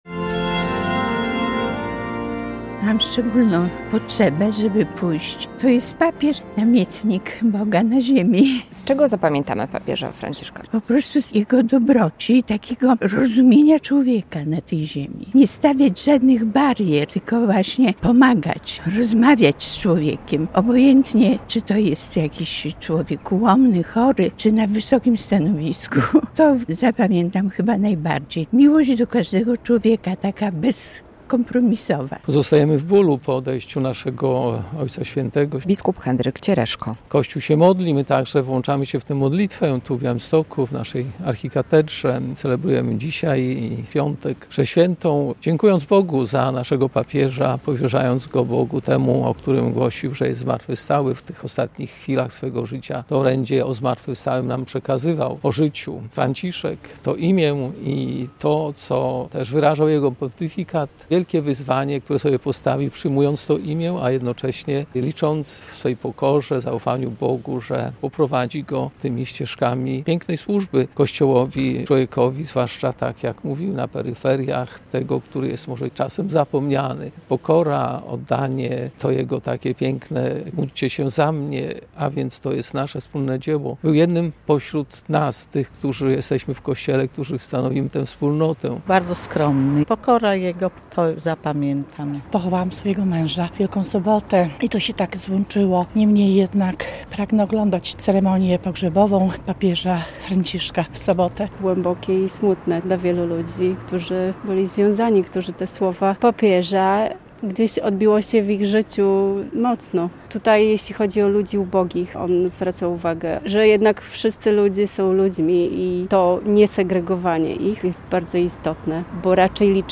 Podlasianie wspominają papieża Franciszka - relacja